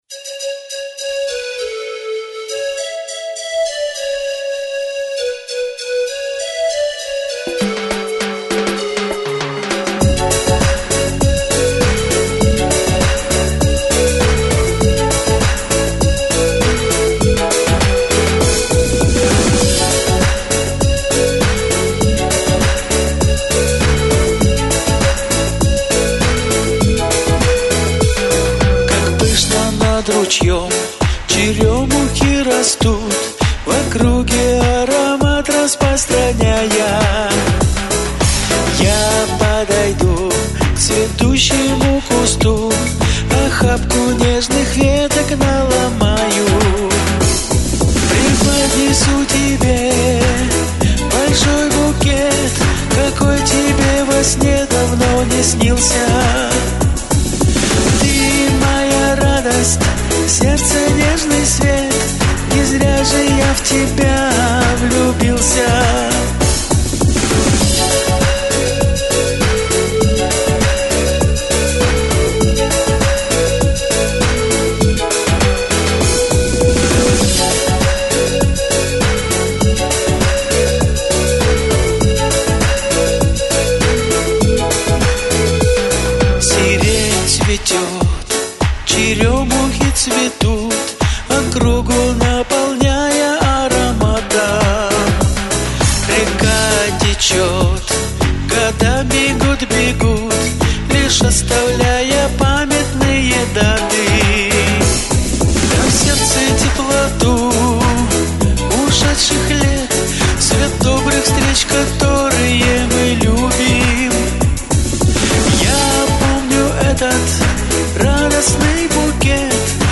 Весёлая, солнечная, спасибо всем, кто над ней работал!